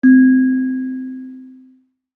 kalimba1_circleskin-C3-pp.wav